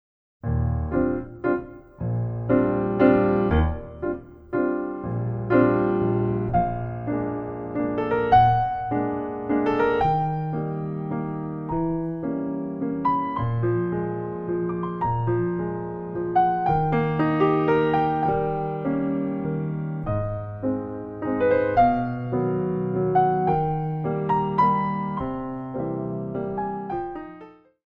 Pirouette (Slower)